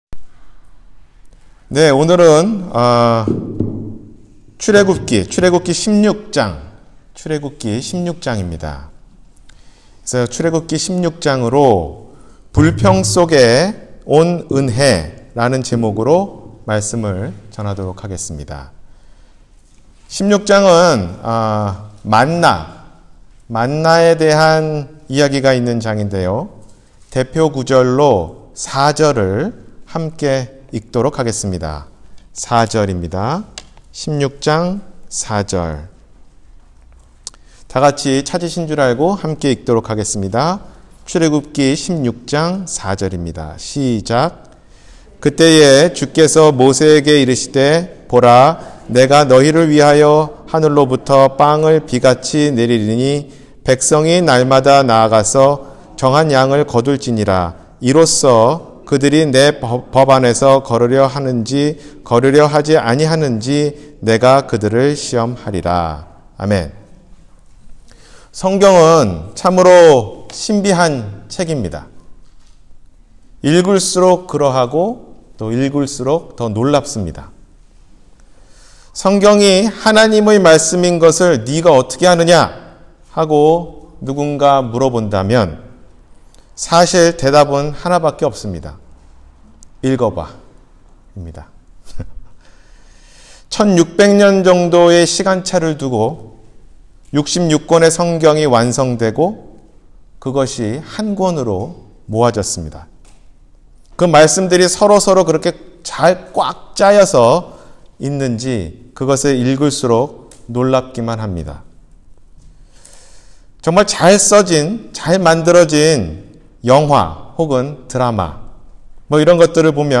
불평 속의 은혜 – 주일설교 – 갈보리사랑침례교회